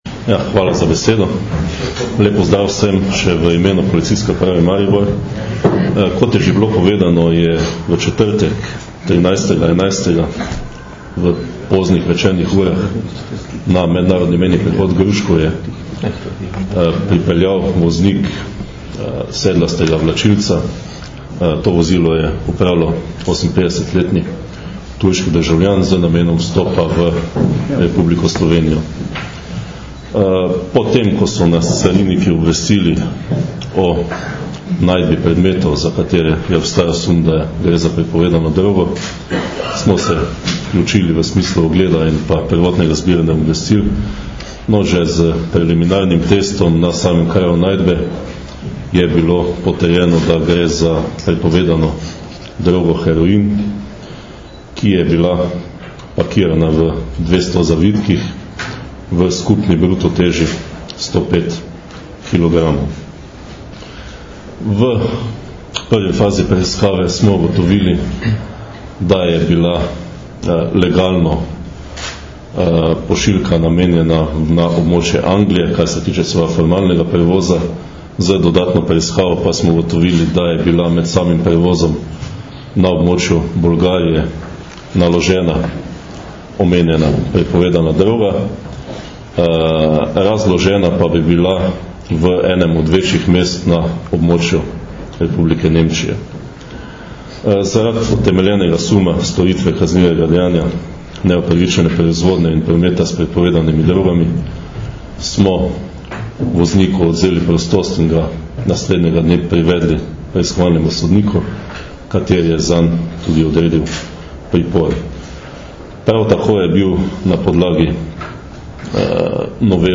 Informacija z novinarske konference: Na današnji novinarski konferenci na Carinskem uradu v Mariboru so predstavniki Carinske uprave RS in Generalne policijske uprave podrobneje predstavili nedavno odkritje večje količine prepovedane droge.